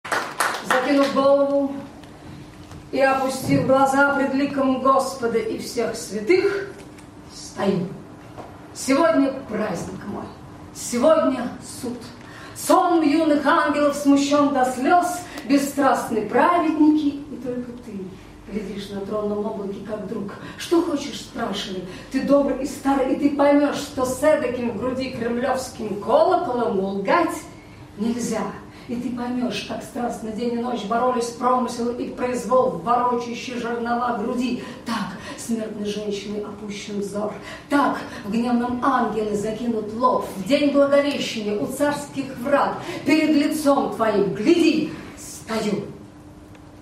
2. «Светлана Сурганова – Закинув голову и опустив глаза… (М.Цветаева – вечер памяти 31.08.2016)» /